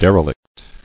[der·e·lict]